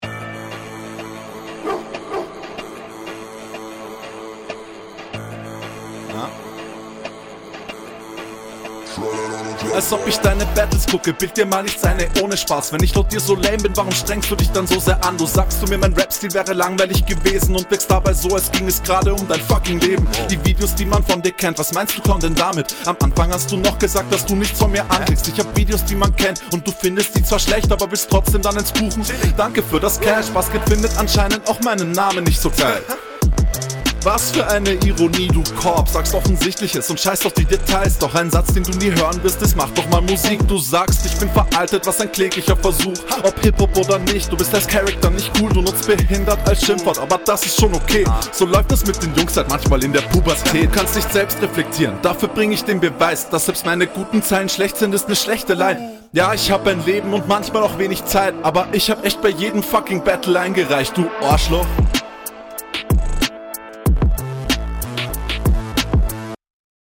Die stimme ist erneut viel zu laut und iwie ist das panning of das ist …